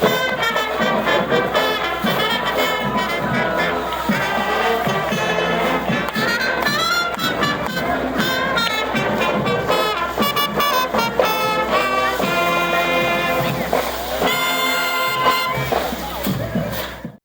Holiday cheer was both visible and audible in downtown Emporia Tuesday night.
4312-parade-sound.wav